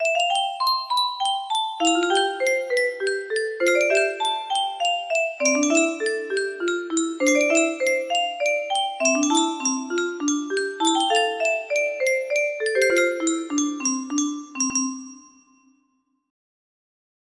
Little Waltz music box melody
A short four-verse waltz, played with an echo.